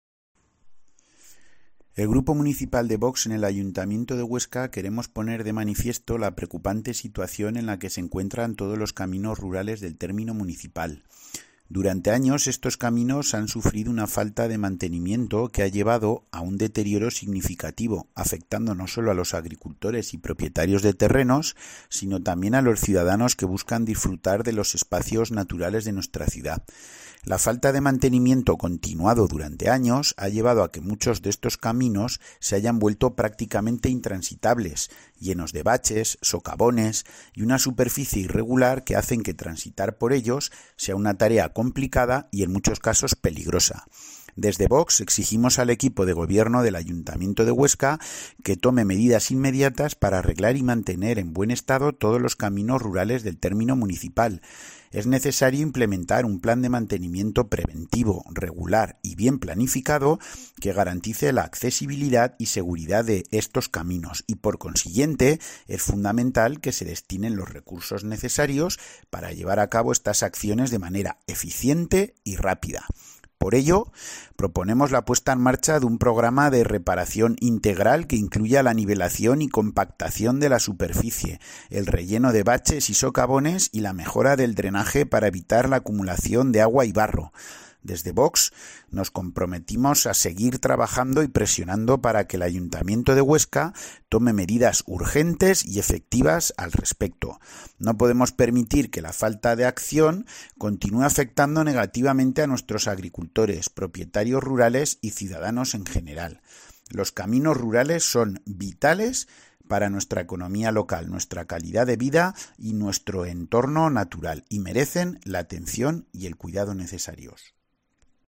El portavoz de VOX en el Ayuntamiento de Huesca, José Luis Rubió